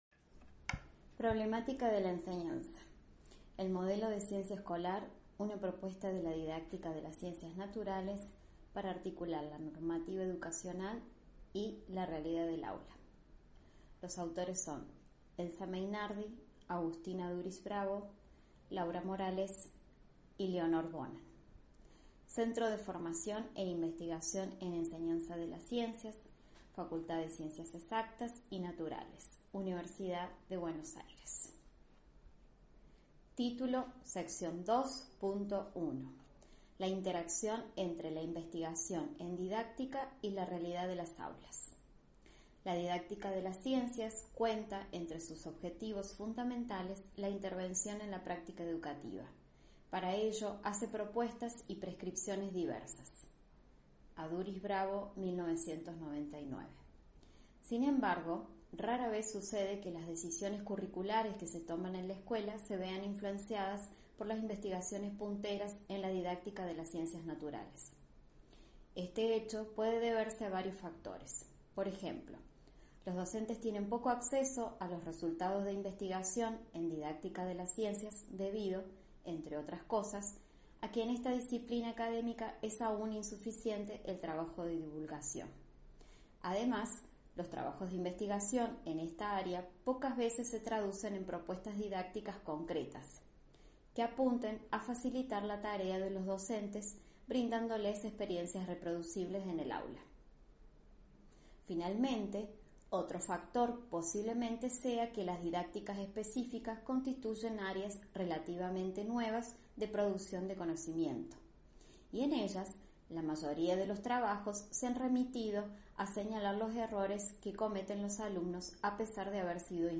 Audio: lectura del artículo sobre Ciencia Escolar ( CC BY-SA ) Cerrar